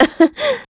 Ripine-Vox_Happy2.wav